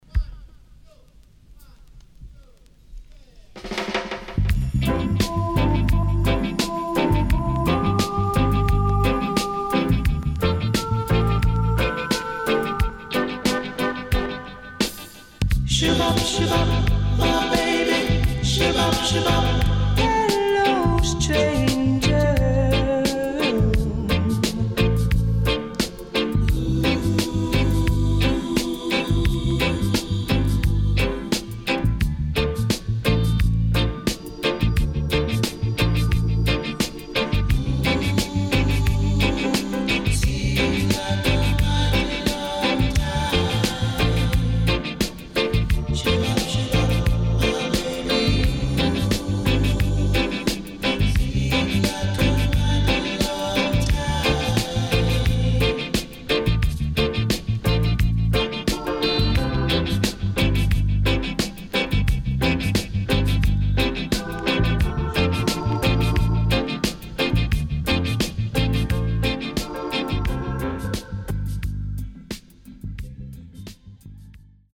CONDITION SIDE A:VG(OK)〜VG+
【12inch】
SIDE A:うすいこまかい傷ありますがノイズあまり目立ちません。